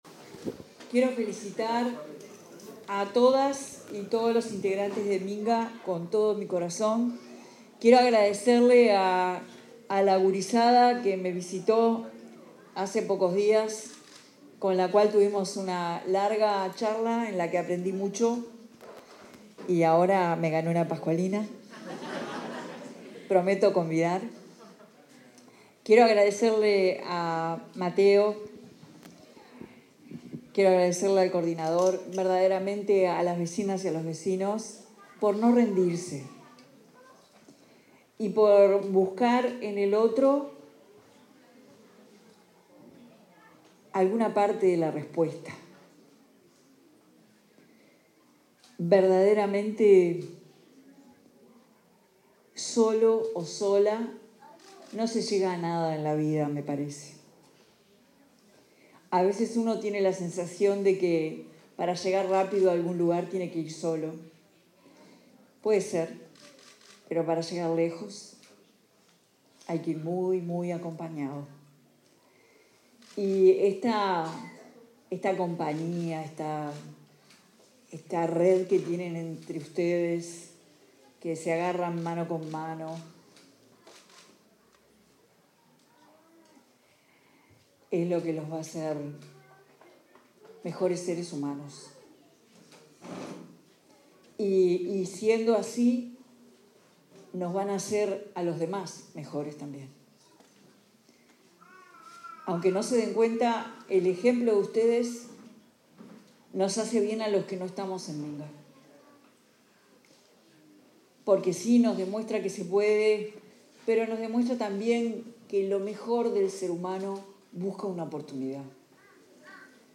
Palabras de la presidenta de la República en ejercicio, Carolina Cosse
La presidenta de la República en ejercicio, Carolina Cosse, expuso en la celebración de los 15 años del Movimiento Minga, en el marco del Día